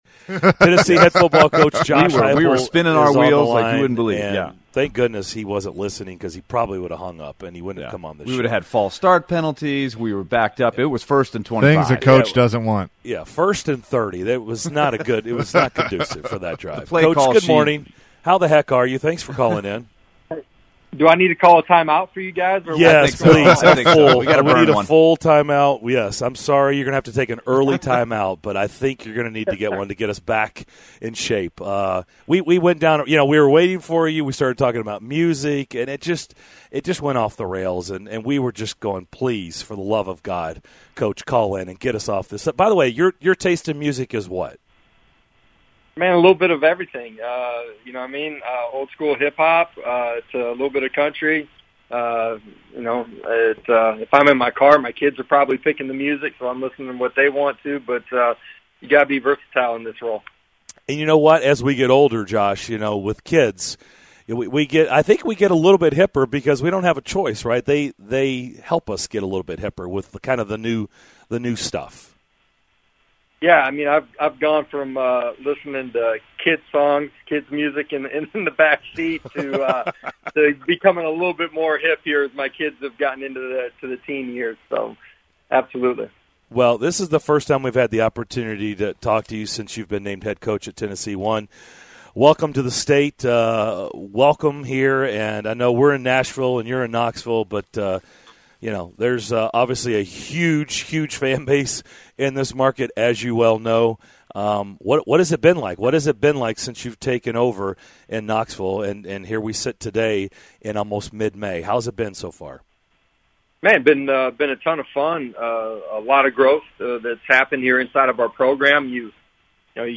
University of Tennessee Head Football Coach Josh Heupel joined DDC for the first time to discuss the progress the team made during spring practice, his adjustment to coaching at UT and more!